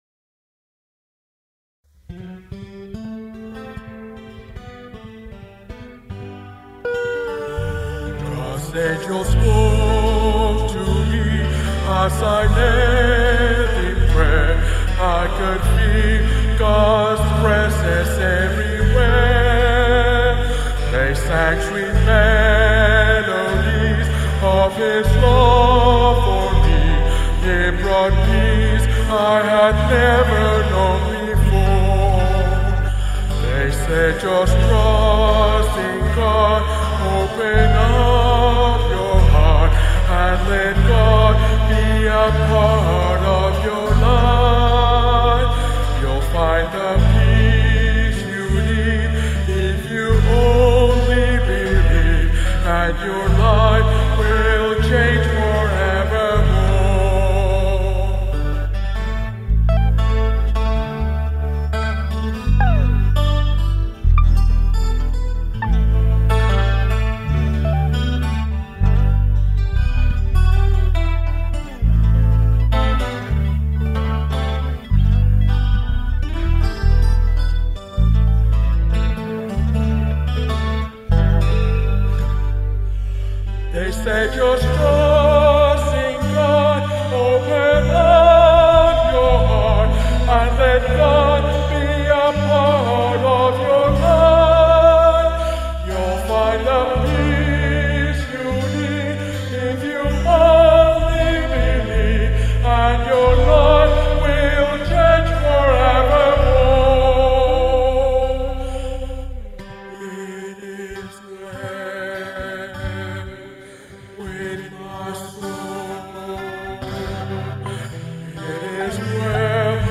a lead sheet and guitar arrangement
church song